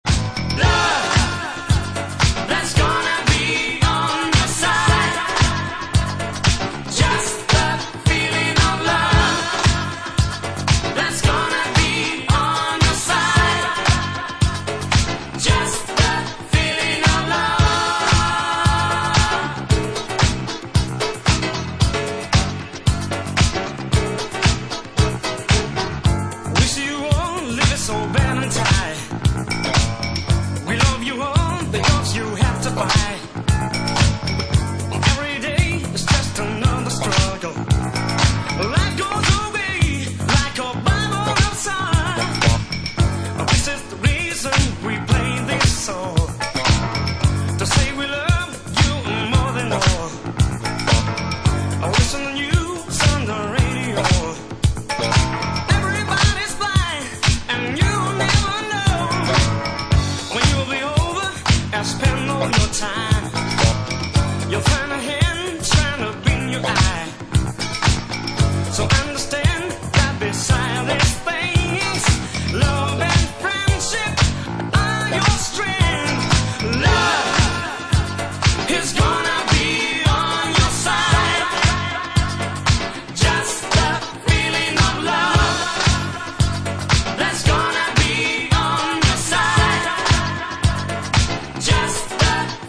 Two early eighties discoclassics on one plate!